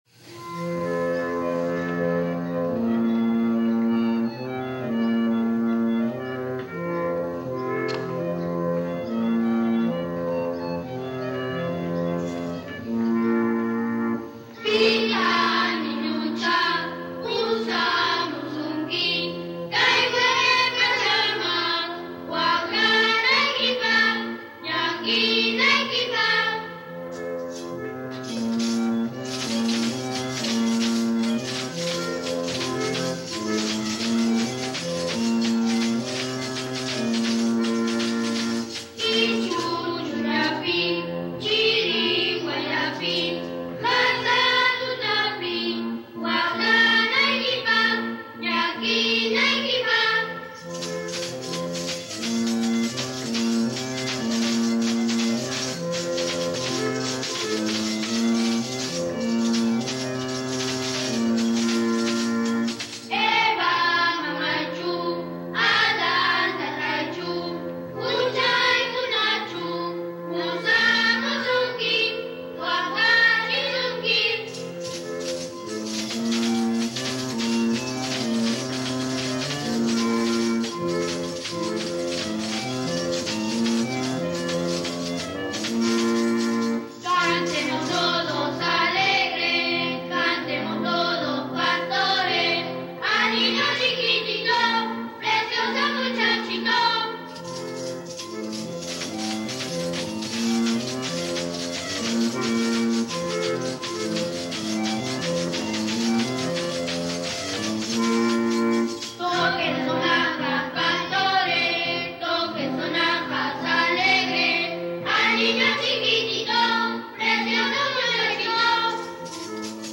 Producción sonora que presenta una selección de registros de expresiones musicales tradicionales vinculadas a la celebración de la Navidad en diversas regiones del Perú.
Cancionero, Canciones navideñas, Villancicos en quechua, Villancicos en español